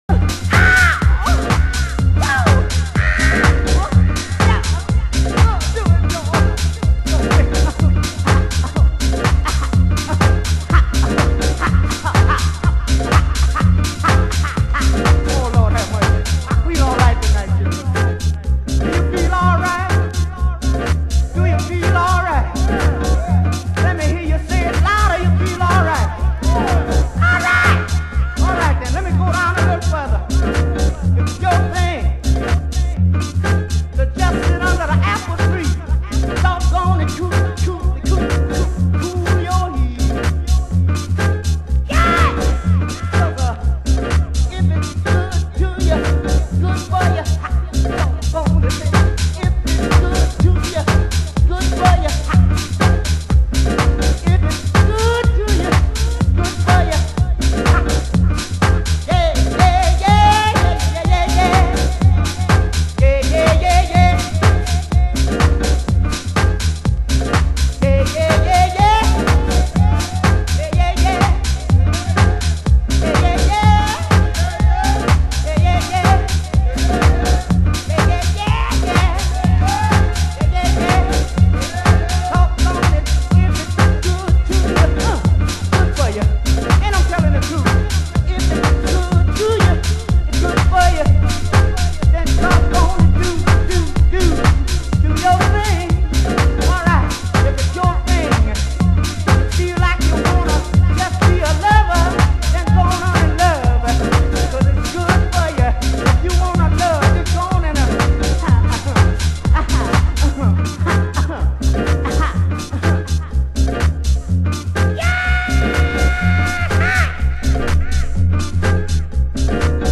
HOUSE MUSIC USED ANALOG ONLINE SHOP